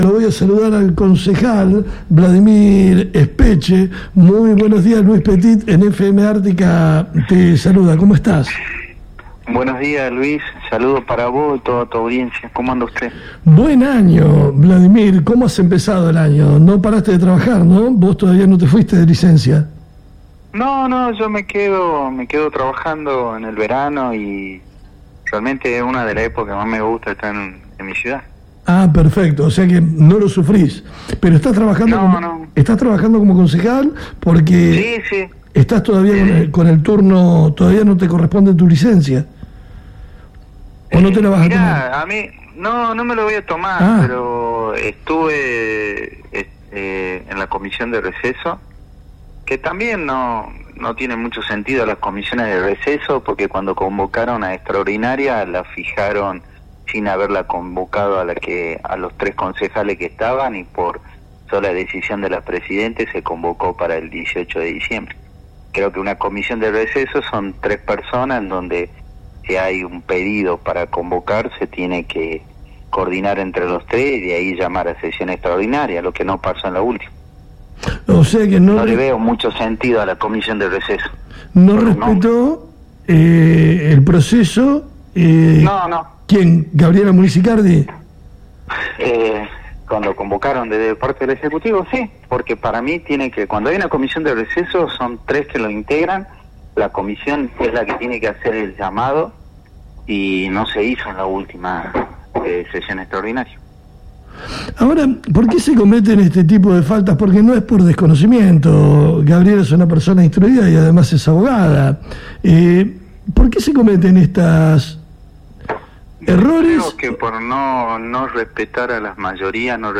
Entrevistamos en FM Ártika al concejal Vladimir Espeche.